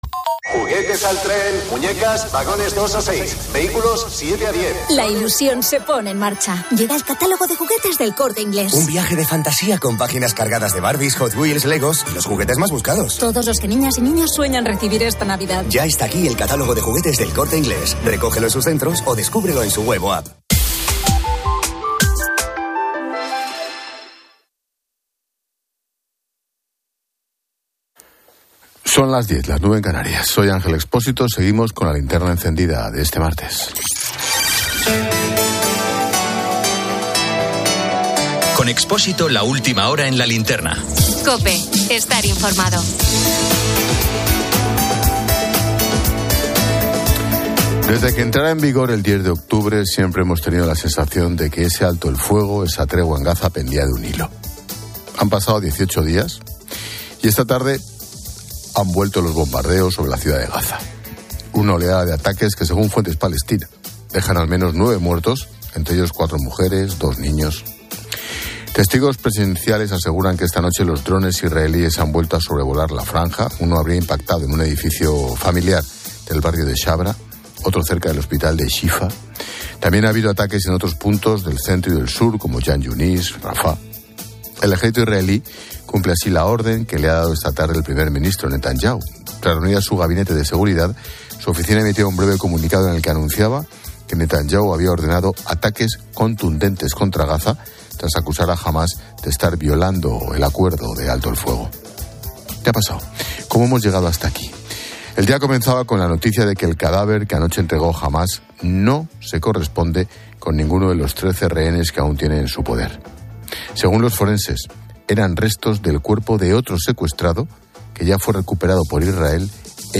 El audio comienza con la publicidad de juguetes de El Corte Inglés, destacando marcas como Barbie y Lego para diferentes edades.
La tertulia de COPE analiza la complejidad del conflicto "milenario".